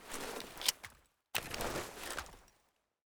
Spas-12 Reanimation / gamedata / sounds / weapons / spas / magcheck.ogg
magcheck.ogg